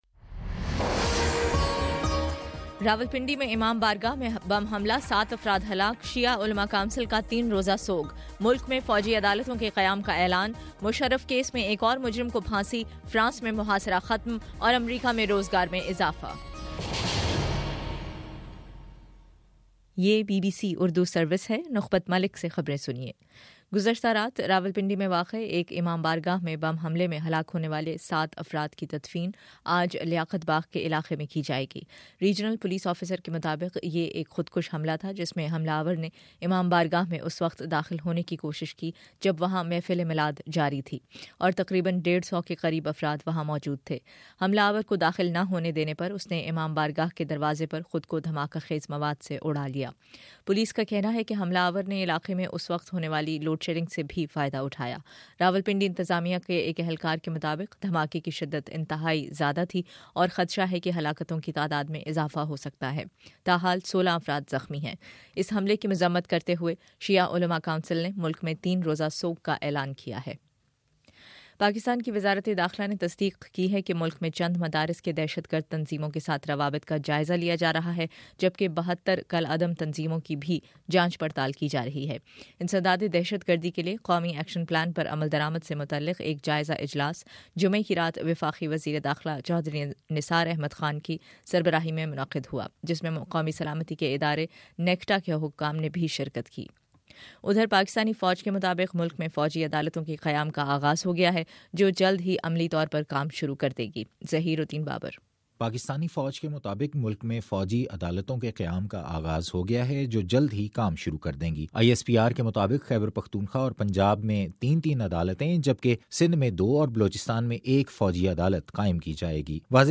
جنوری10 : صبح نو بجے کا نیوز بُلیٹن